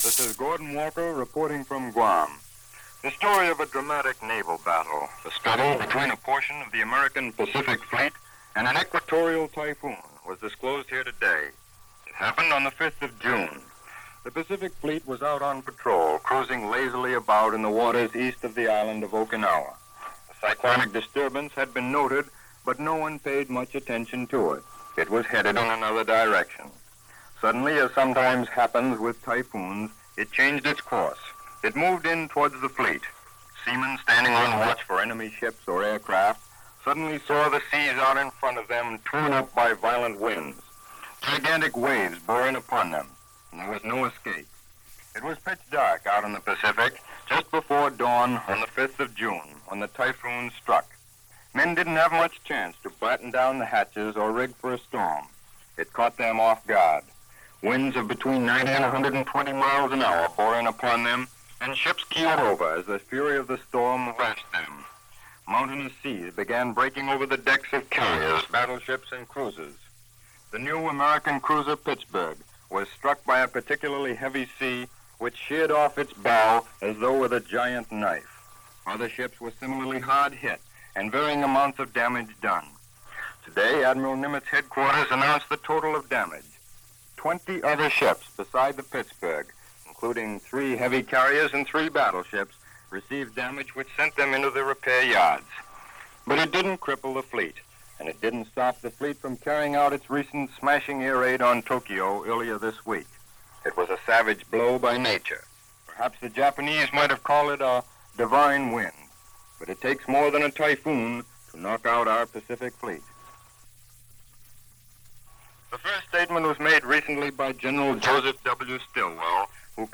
July 13, 1945 - A Typhoon revealed - The Coming Invasion Or not - Atrocities in Manila - news reports from the Pacific Front, from Mutual.